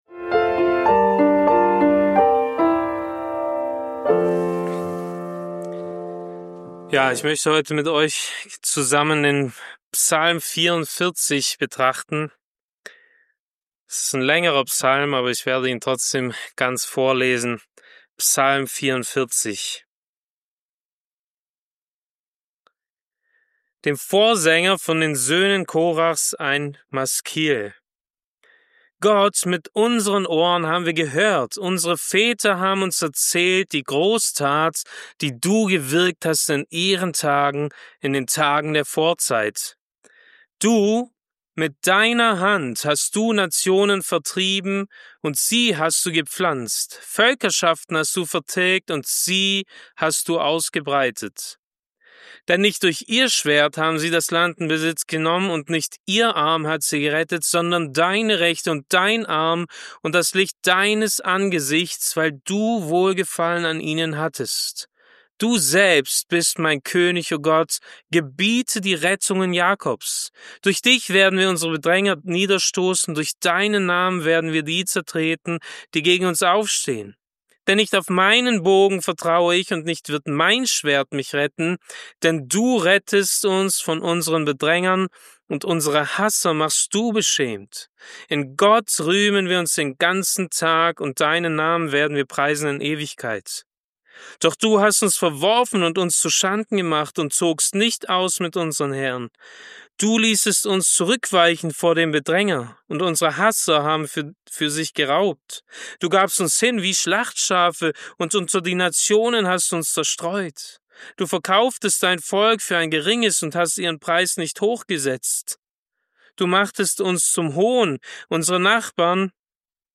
Diese Predigt zu Psalm 44 lehrt, wie man Gott in schweren Zeiten treu bleibt, indem man auf theoretisches Wissen über Gottes Wesen aufbaut und dieses Wissen durch Glauben praktisch anwendet.